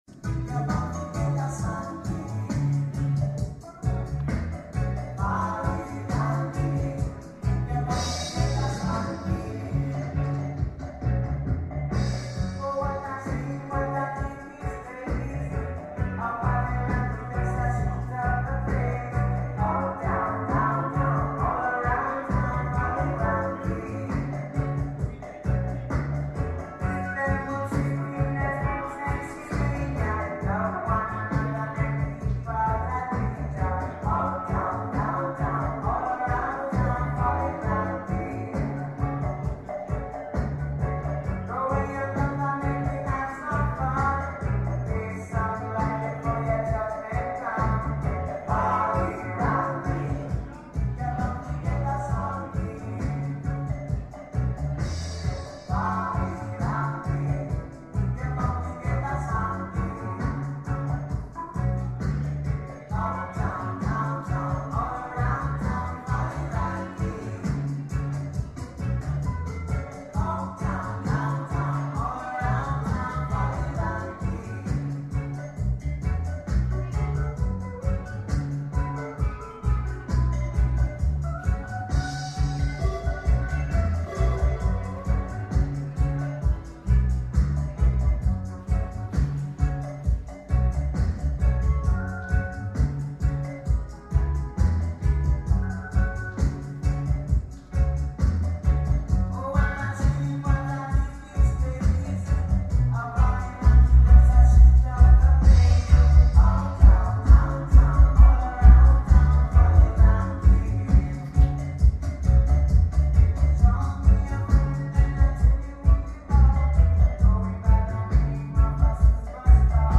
2 SCOOP STACK IN THIS VENUE. UNFORTUNATELY THE LAST 1.5 HRS DID'NT RECORD FULLJOY!